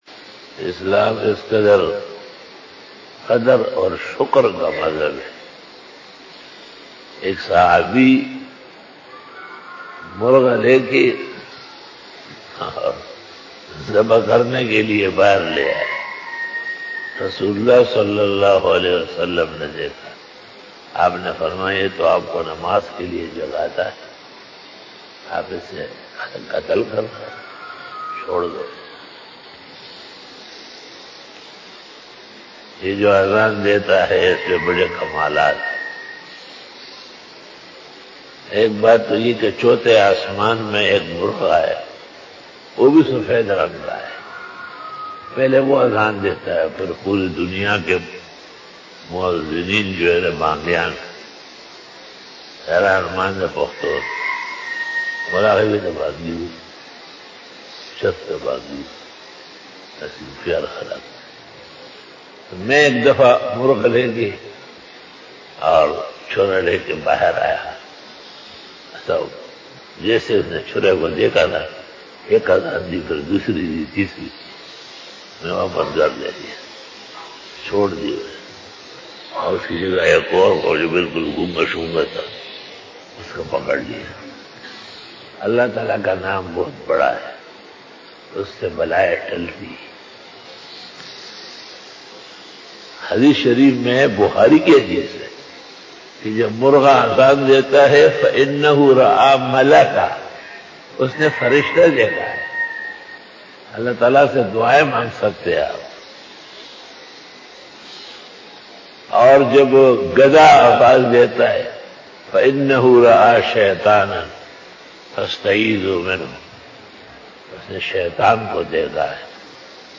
Fajar bayan 13_ September_ 2020 (24 Muharram 1442_HJ) Sunday
بعد نماز فجر بیان 13 ستمبر 2020ء بمطابق 24 محرم الحرام 1442ھ بروزاتوار